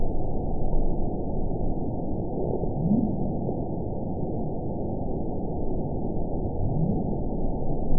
event 920916 date 04/15/24 time 06:24:19 GMT (1 year, 2 months ago) score 6.90 location TSS-AB07 detected by nrw target species NRW annotations +NRW Spectrogram: Frequency (kHz) vs. Time (s) audio not available .wav